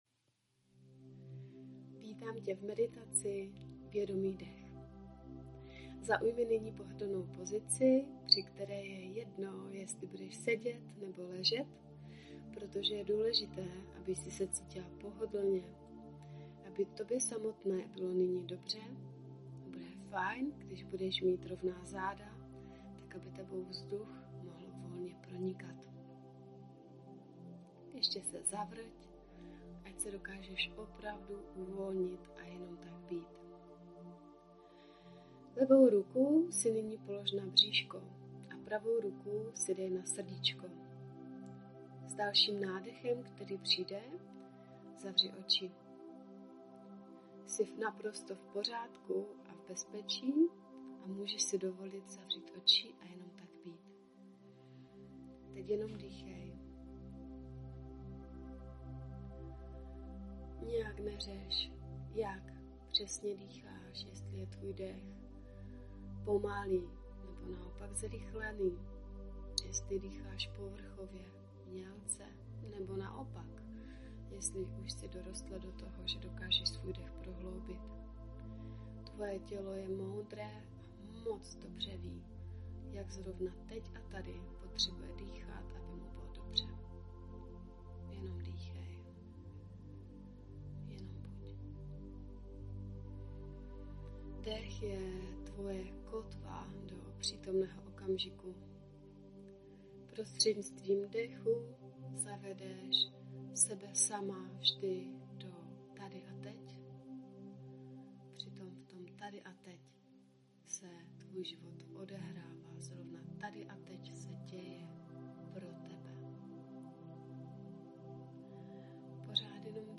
Meditace Vědomý dech
Meditace-vedomy-dech.mp3